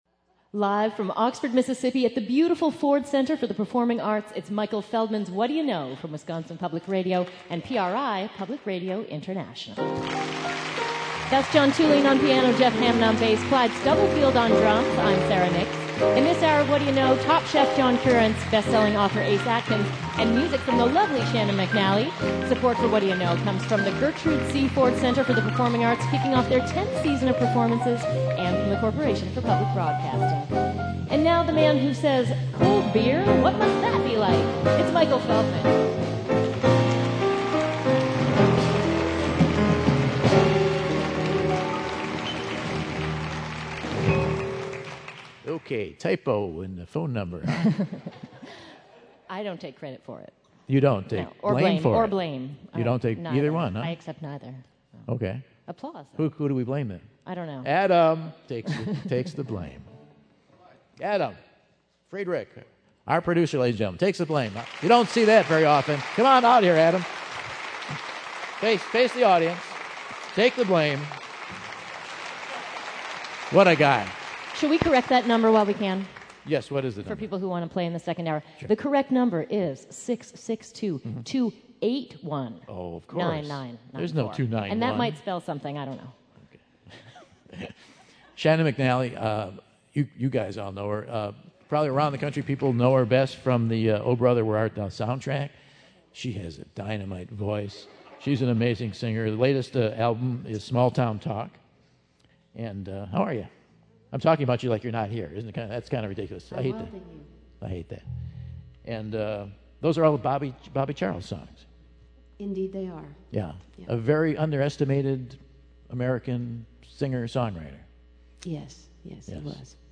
Southern steeped singer-songwriter